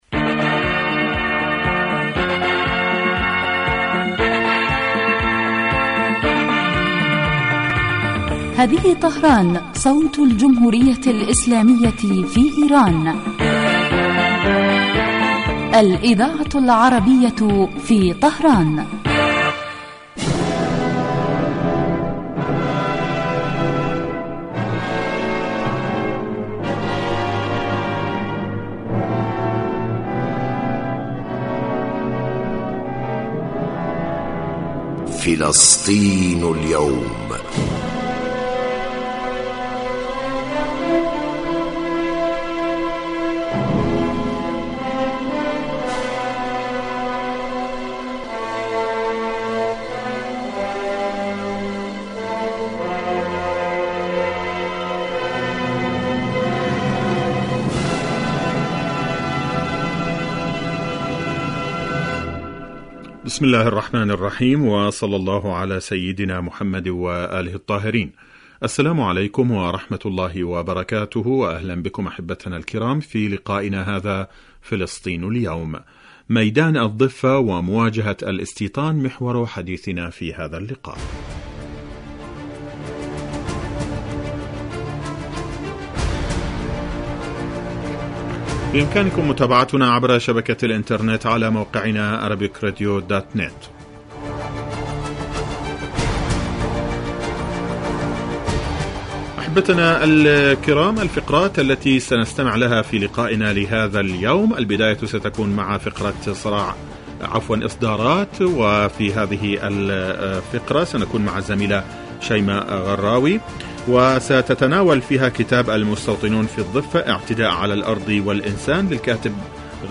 فلسطين اليوم برنامج يتناول تطورات الساحة الفلسطينية على كافة الصعد من خلال تقارير المراسلين واستضافة الخبراء في الشأن الفلسطيني.